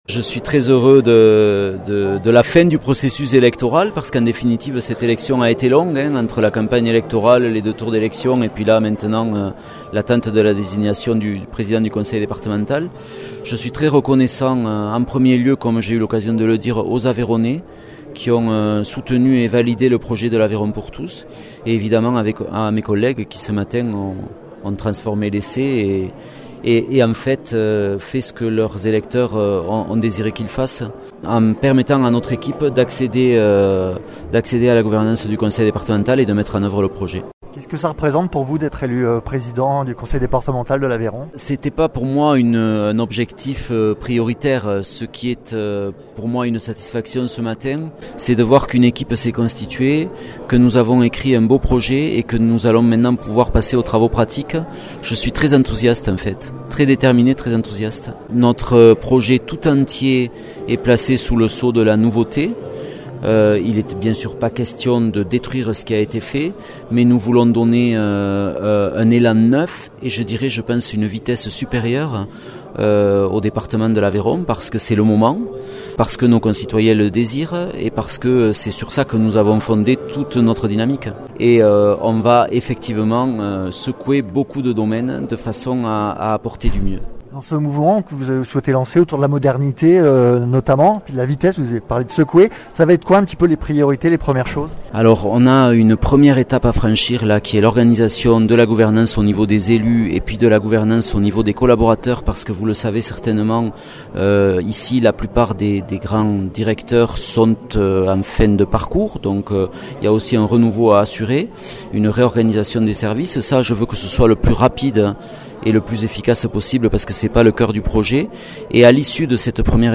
Interviews
Invité(s) : Arnaud Viala, Président du conseil départemental de l’Aveyron